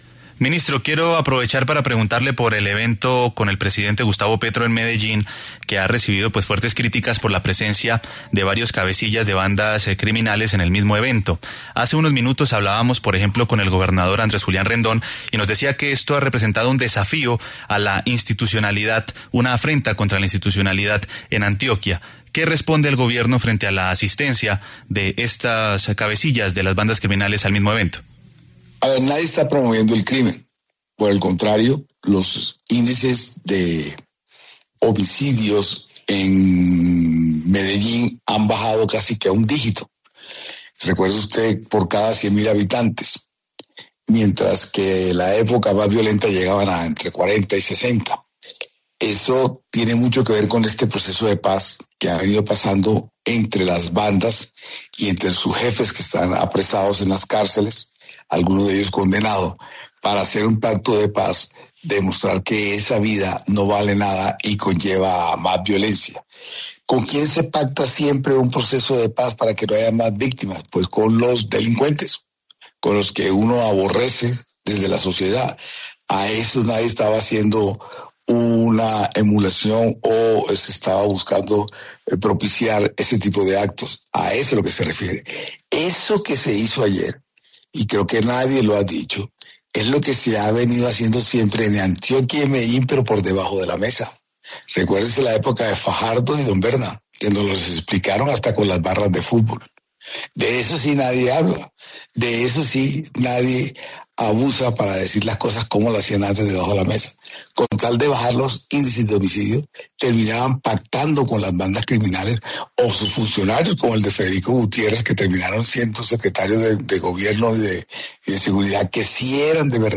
Armando Benedetti, ministro del Interior, habla en La W